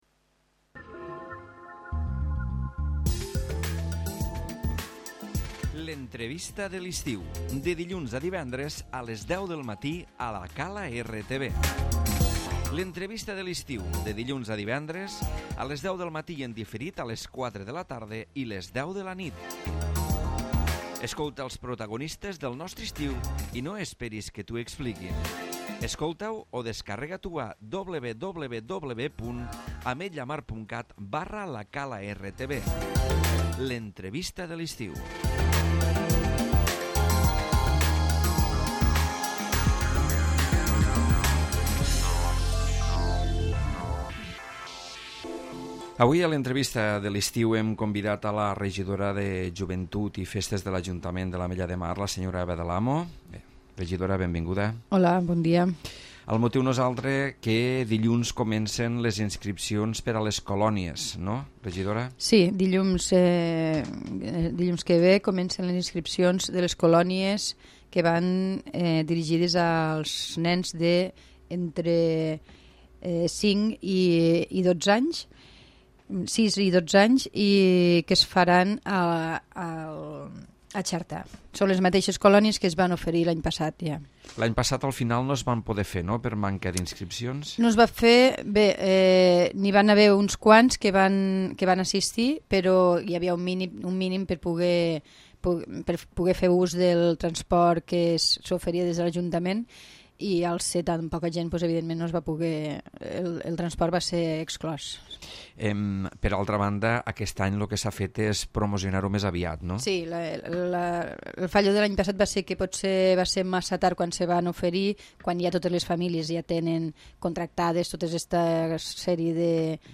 L'Entrevista de l'estiu
Eva del Amo, regidora de Joventut de l'Ajuntament de l'Ametlla de Mar parla avui de l'inici del període d'inscripcions per a les Colonies de setembre a Xerta.